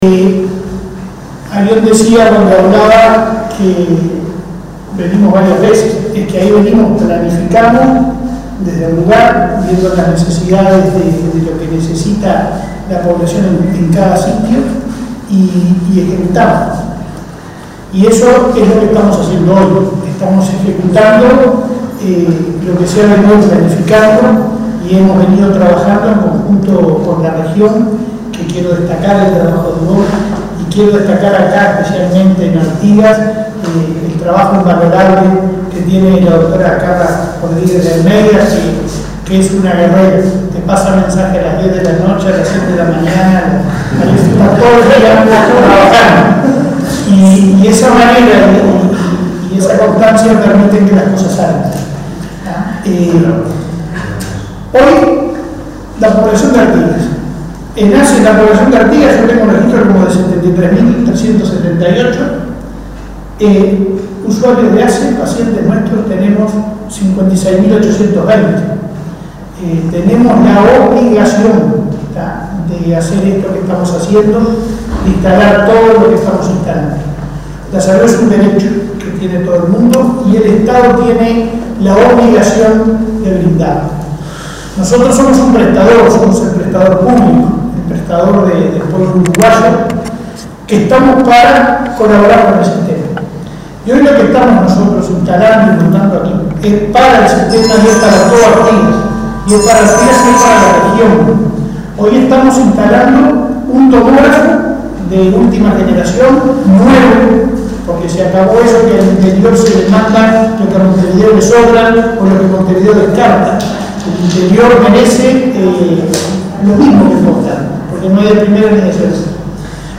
Palabras del presidente de ASSE, Leonardo Cipriani
Palabras del presidente de ASSE, Leonardo Cipriani 04/03/2022 Compartir Facebook X Copiar enlace WhatsApp LinkedIn El presidente de ASSE, Leonardo Cipriani, visitó el pasado jueves 3 el hospital de Artigas, que se convirtió en la sede norte del Hospital Especializado de Ojos y donde se instaló un nuevo tomógrafo.